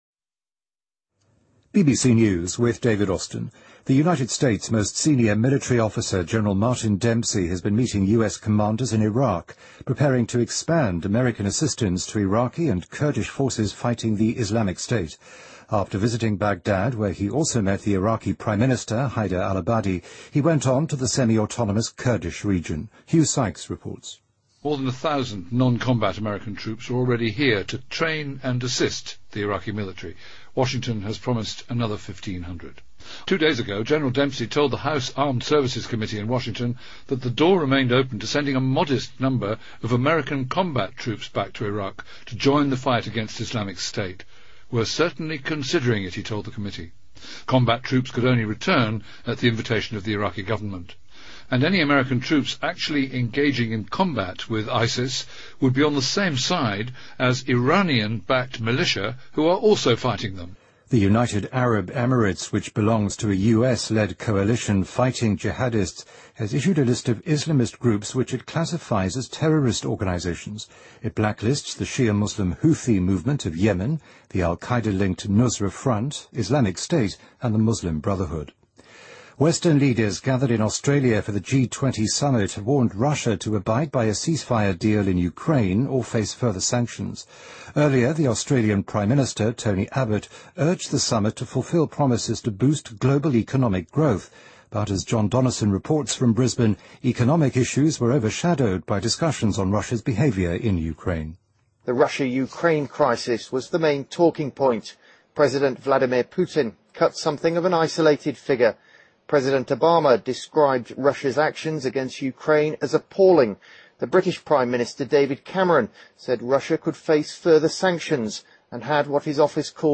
BBC news,阿拉伯联合酋长国宣布恐怖组织黑名单